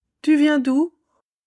#6. Listen to this. What is she saying?